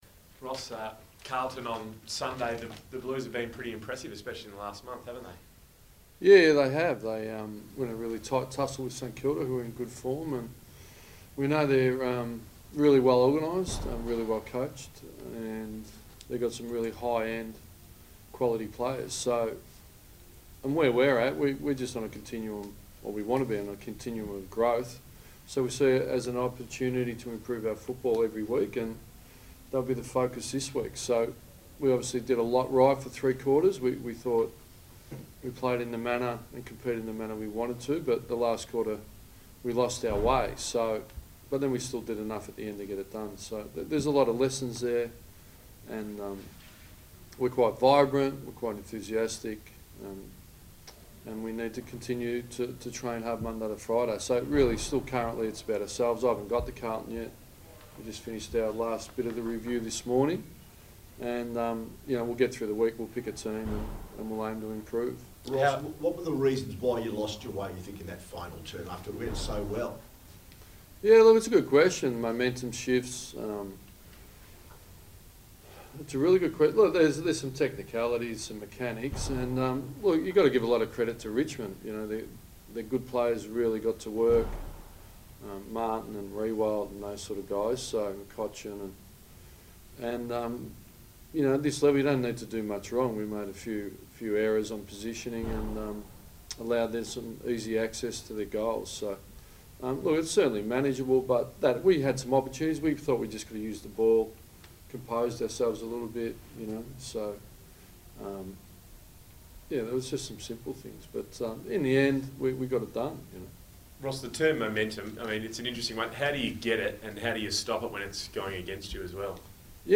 Ross Lyon media conference: Wednesday 17 May 2017
Ross Lyon spoke to the media ahead of the clash against Carlton